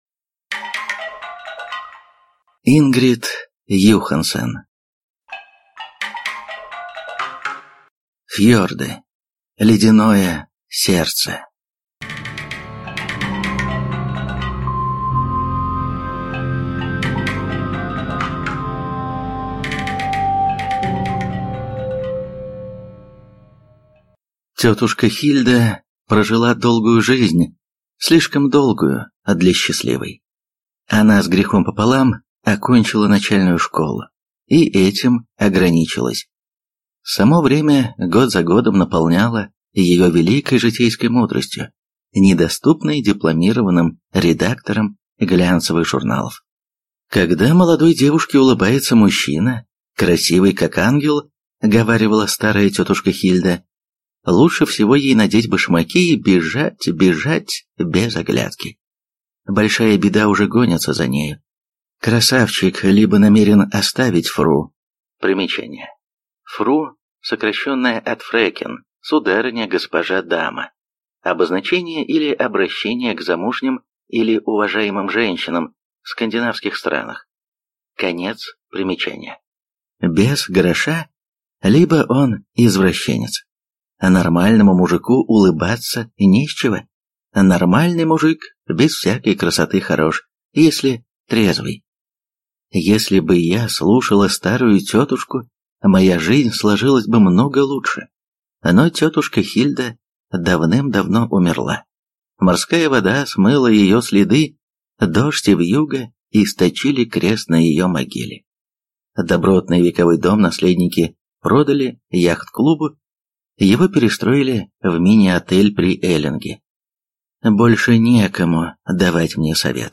Аудиокнига Фьорды. Ледяное сердце | Библиотека аудиокниг